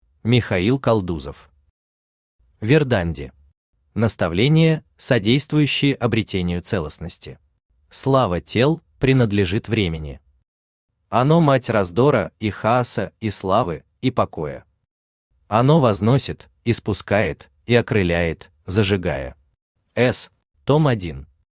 Наставления, содействующие обретению целостности Автор Михаил Константинович Калдузов Читает аудиокнигу Авточтец ЛитРес.